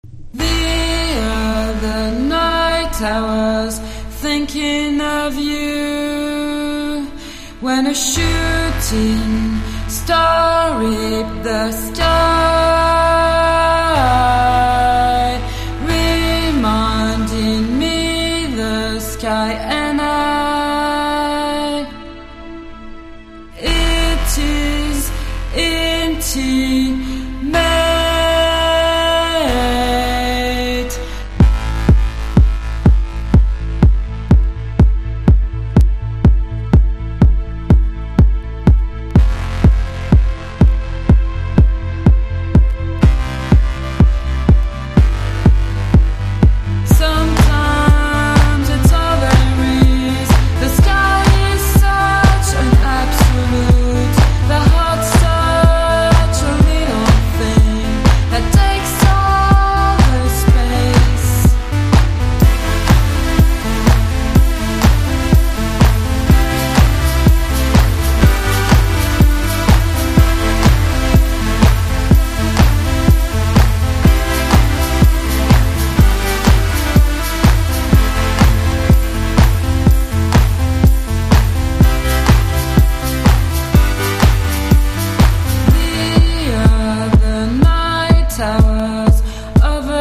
80`Sのイナタさと前衛的なシンセポップ感が見事に融合した一曲！！